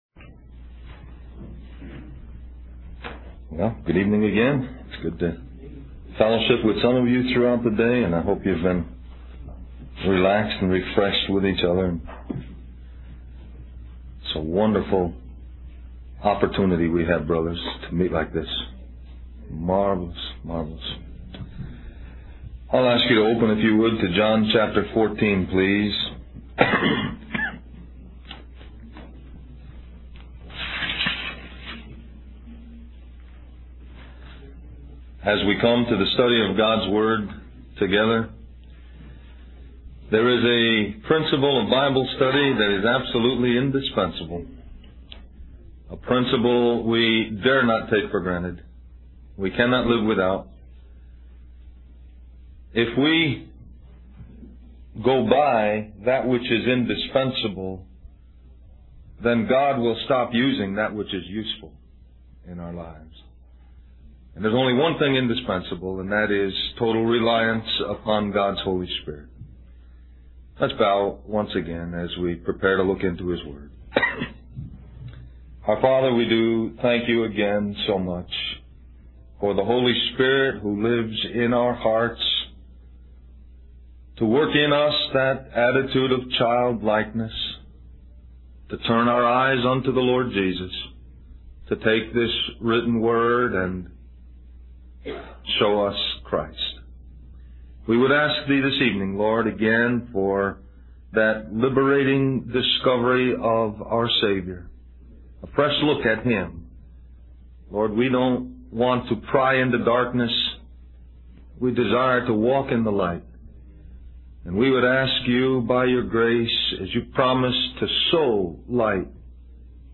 A collection of Christ focused messages published by the Christian Testimony Ministry in Richmond, VA.
Del-Mar-Va Labor Day Retreat